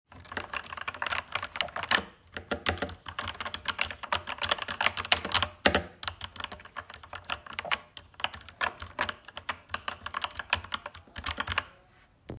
Keyboard’s Noise While Typing
A fine execution with the dampening materials produces the anticipated result, especially compared to other keyboards without foam.
Corsair-K70-Core-Noise-During-Typing.mp3